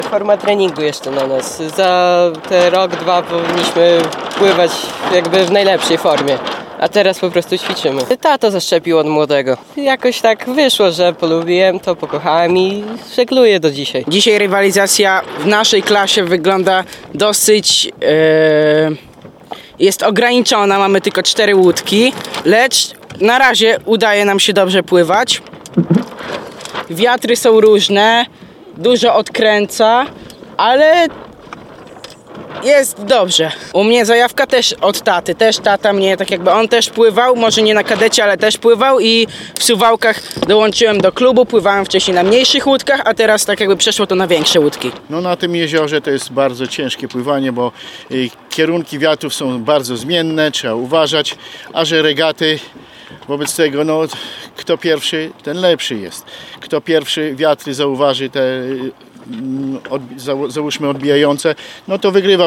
Posłuchajmy, co mówili żeglarze.